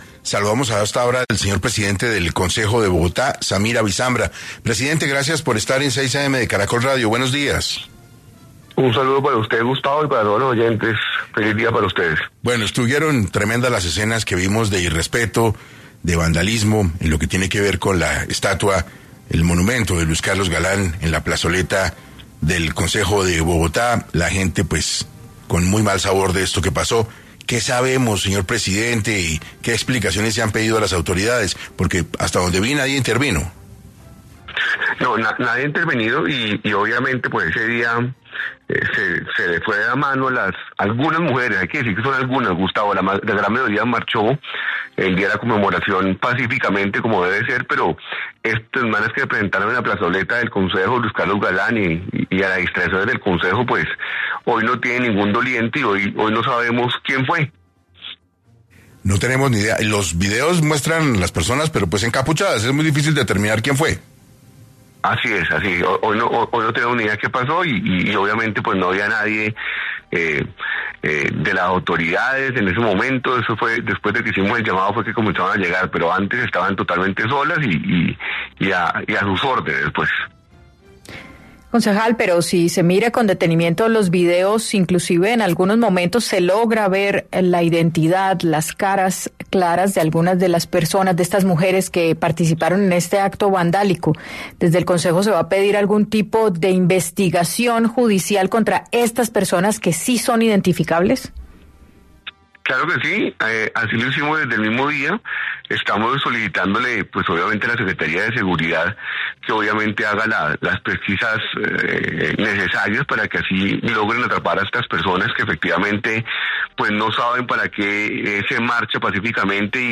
Hoy en entrevista para 6AM, Samir Abisambra, presidente del Concejo de Bogotá, habló sobre los daños a bienes públicos que se presentaron el 8 de marzo en Bogotá.